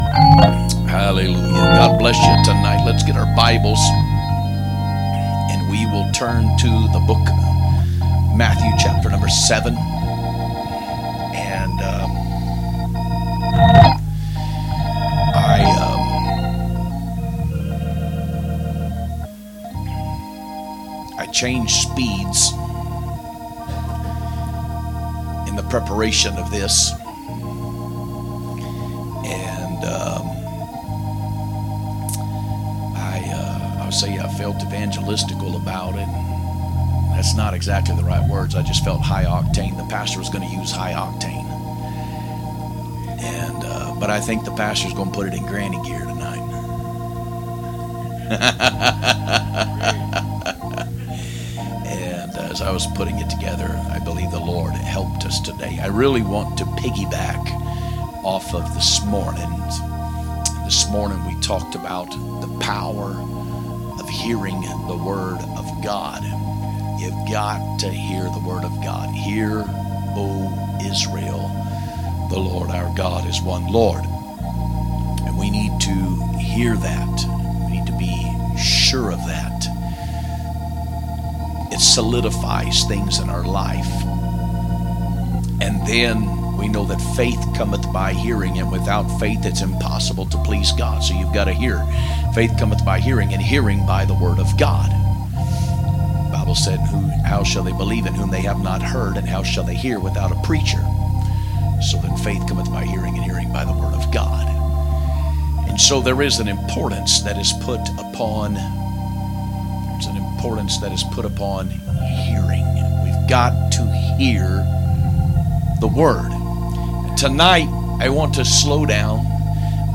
2/23/2025 Sunday Evening Service